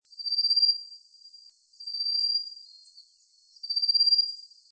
24-2阿里山棕面鶯2.mp3
物種名稱 棕面鶯 Abroscopus albogularis fulvifacies
錄音地點 嘉義縣 阿里山 阿里山
25-30 錄音環境 森林 發聲個體 行為描述 鳴唱 錄音器材 錄音: 廠牌 Denon Portable IC Recorder 型號 DN-F20R 收音: 廠牌 Sennheiser 型號 ME 67 標籤/關鍵字 鳥 備註說明 MP3檔案 24-2阿里山棕面鶯2.mp3